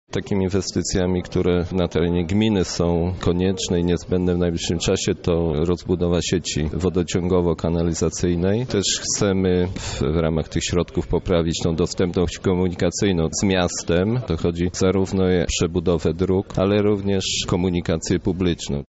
O korzyściach, jakie niesie ta umowa, mówi Jacek Anasiewicz, wójt gminy Głusk